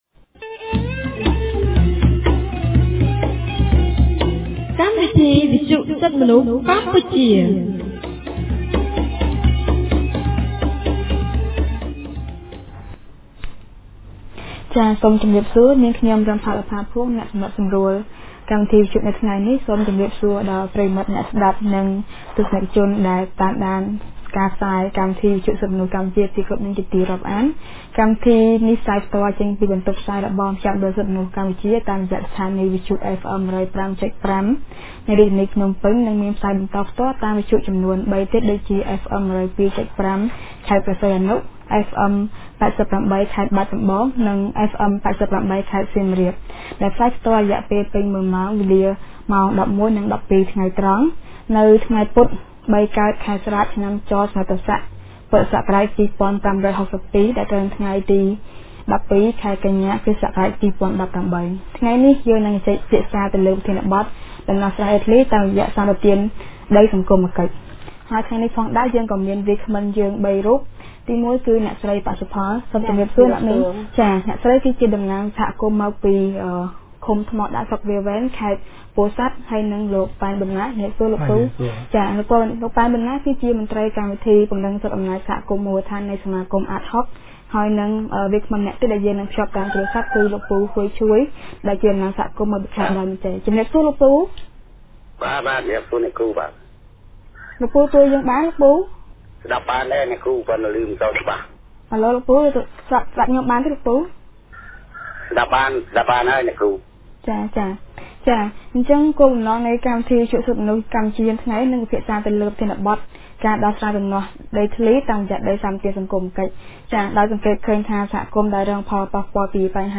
On Wednesday 12th September 2018, CCHR’s radio program held a talk show on the topic”Settlement of Land Disputes Through Social Land Concessions.”